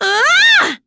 Nicky-Vox_Attack2.wav